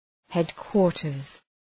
{‘hed,kwɔ:rtərz}